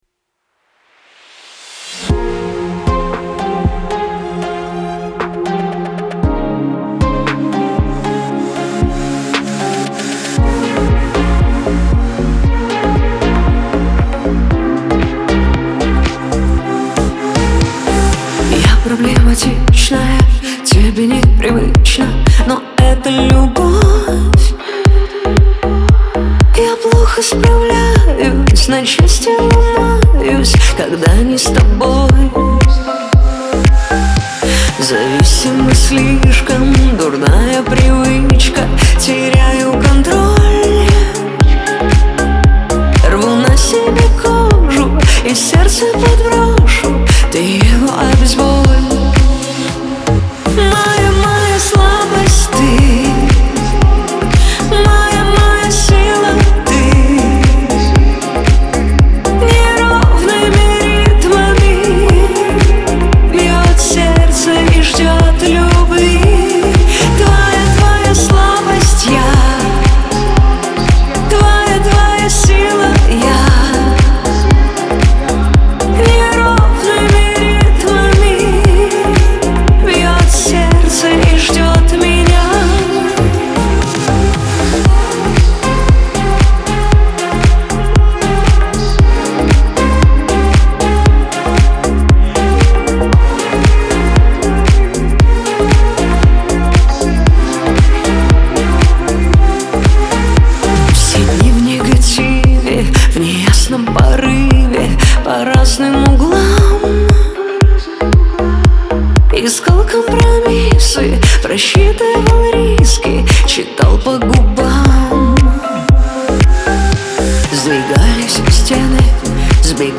Лирика
диско